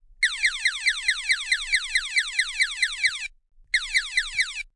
小小的玩具枪" 玩具枪1
描述：在我的声乐室里录制了一把小玩具枪。 用RØDENT2A录制。
标签： 科幻 手枪 射击 效果 武器 FX 空间 SFX pewpew 激光 玩具
声道立体声